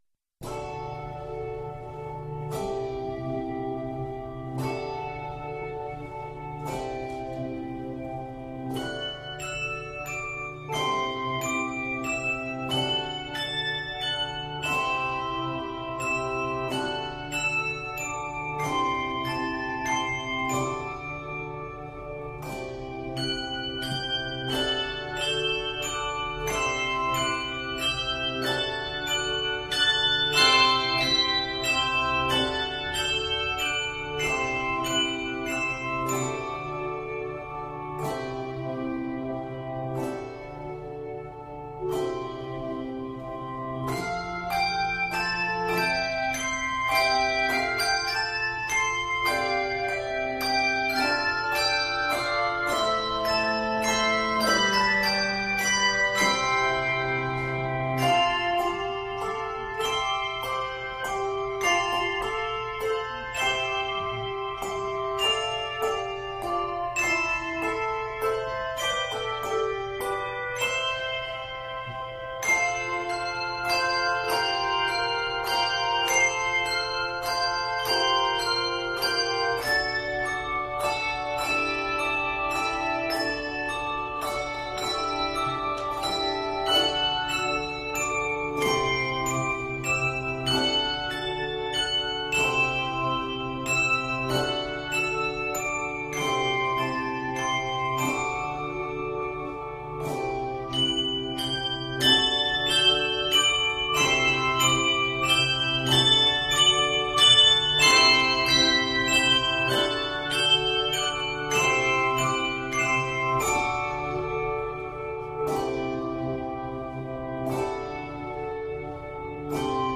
Heartwarming and full of gentle grace